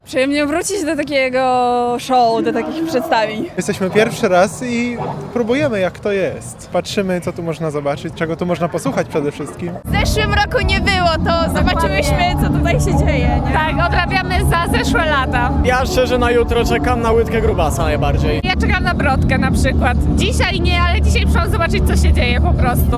– Przyjemnie wrócić do takiego show, do takich przedstawień – mówi jedna ze studentek.
studenci-juwenalia.mp3